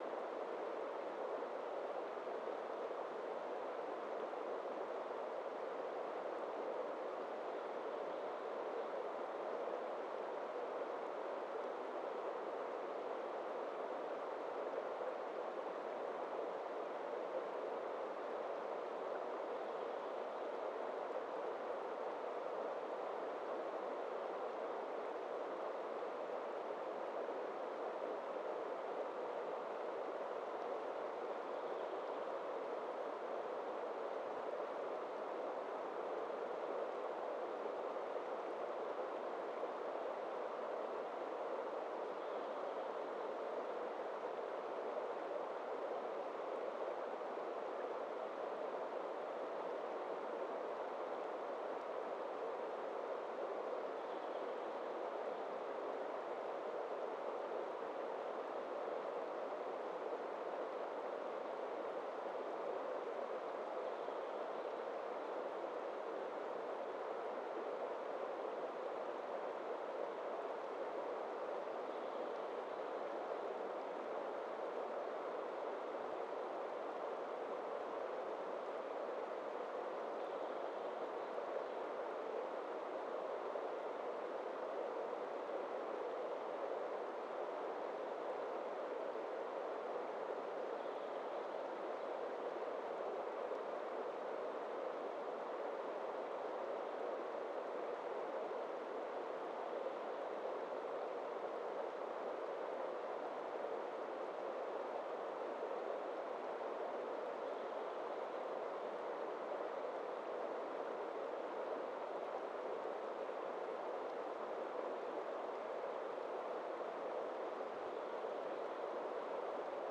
Quellrauschen in Bänder geteilt 500.wav